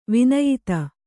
♪ vinayita